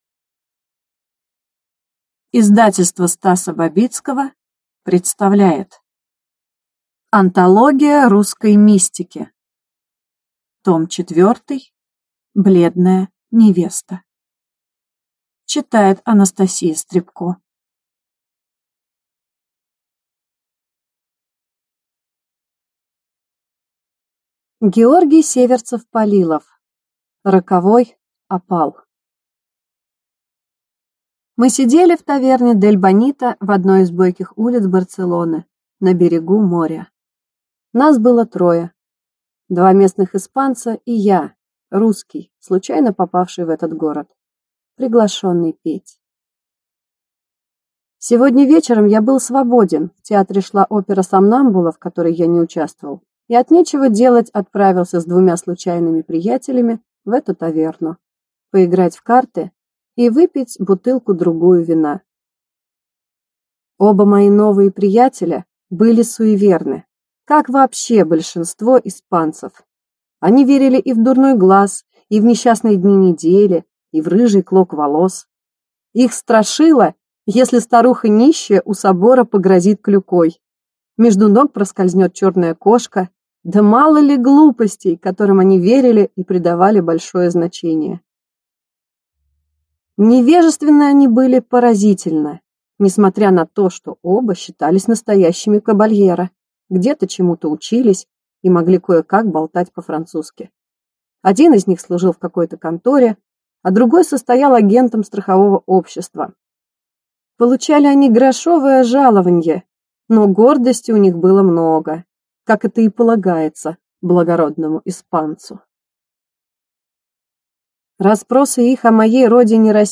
Аудиокнига Бледная невеста | Библиотека аудиокниг